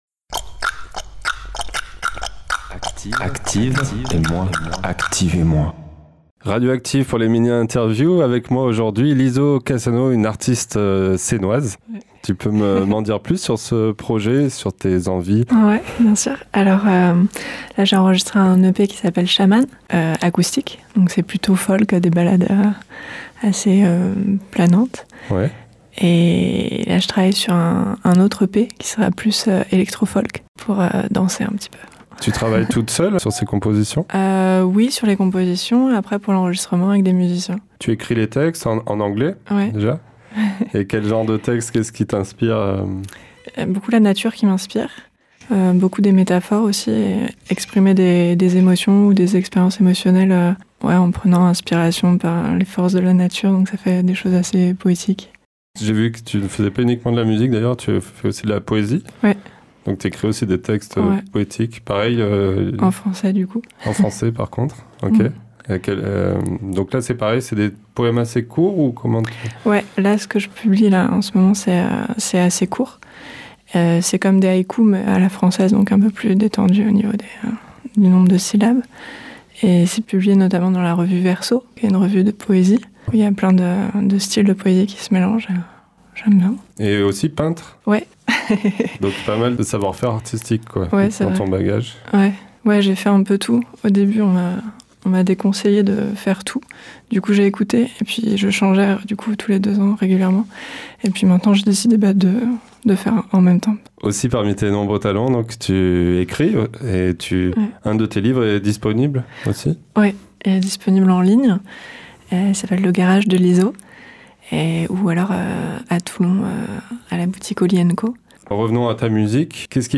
Entretien réalisé par